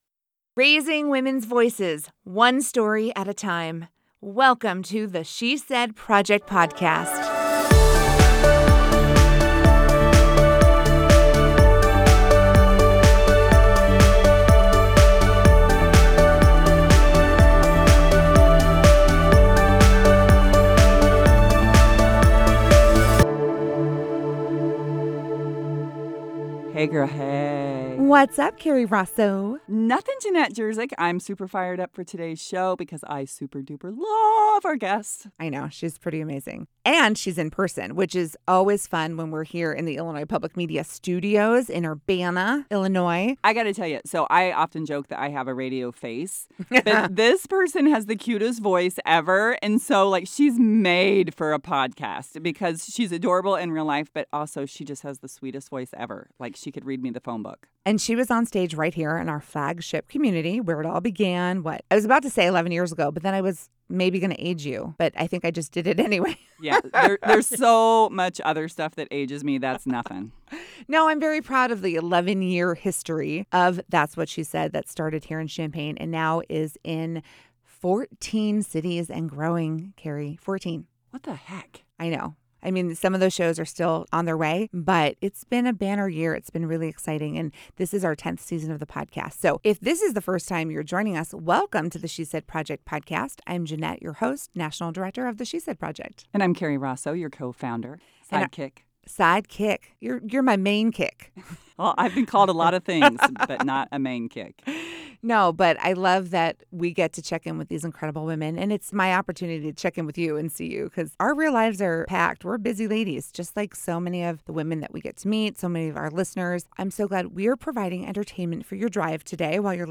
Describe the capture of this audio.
The She Said Project Podcast is recorded in partnership with Illinois Public Media.